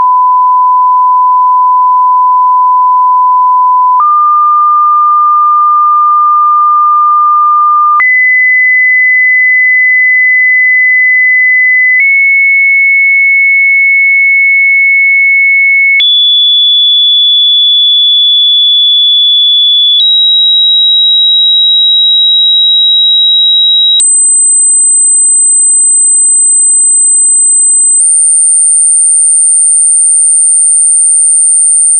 frequenza_test_02.mp3